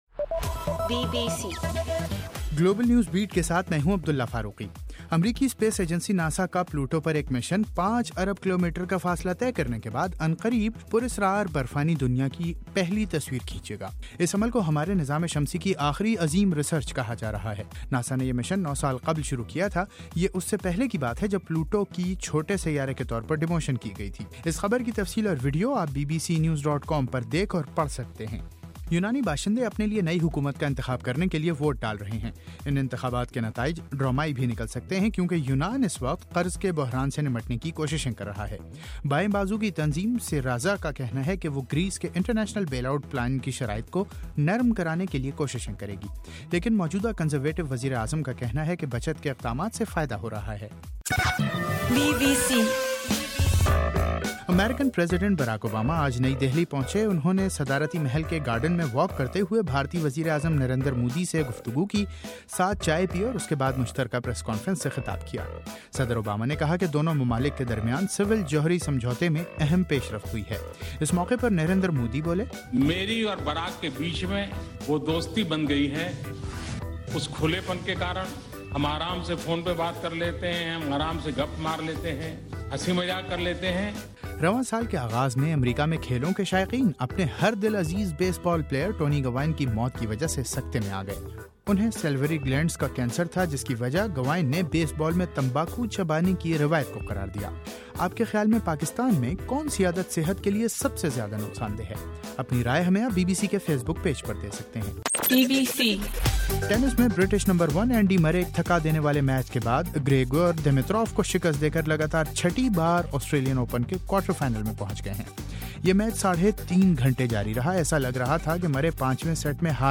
جنوری 25: رات 10 بجے کا گلوبل نیوز بیٹ بُلیٹن